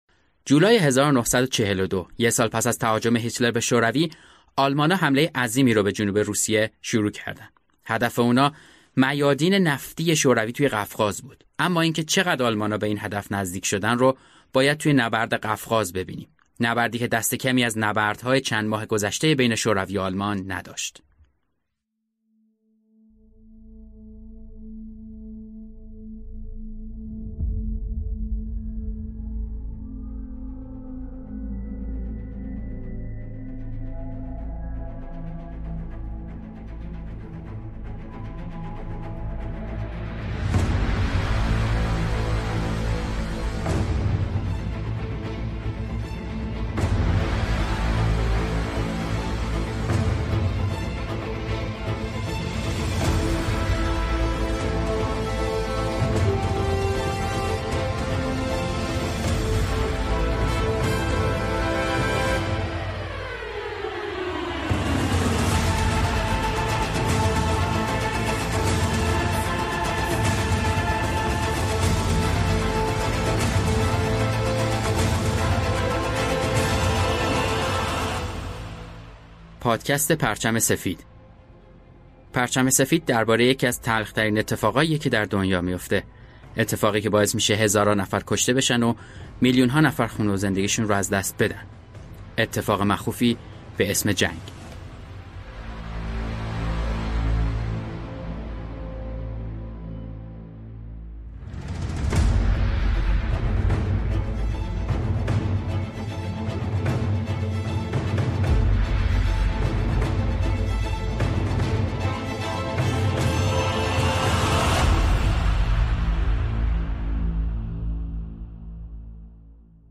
صداهایی که در پادکست می‌شنوید هم از یوتیوب و ویدئوهای مستند استفاده شده است.